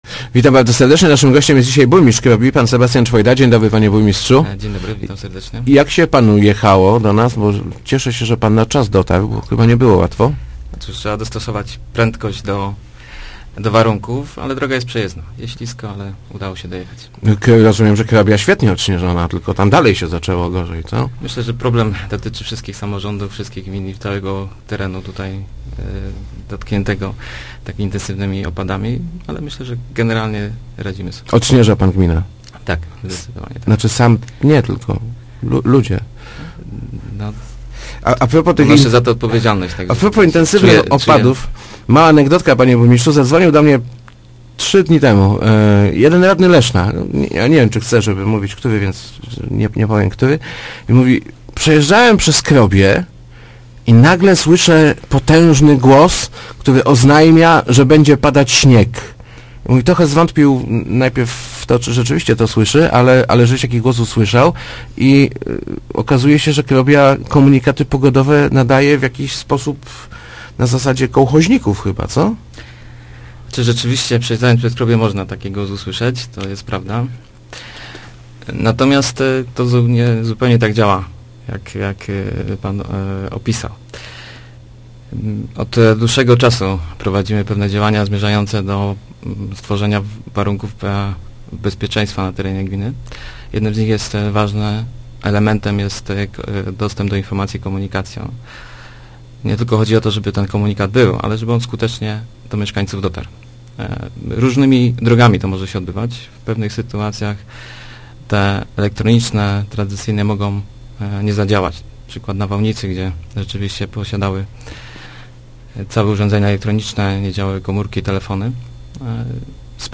Nie robimy tego zbyt często – mówił w Rozmowach Elki burmistrz Sebastian Czwojda – ale pomysł okazał się bardzo skuteczny, zwłaszcza przy ostrzeganiu o niebezpiecznej pogodzie.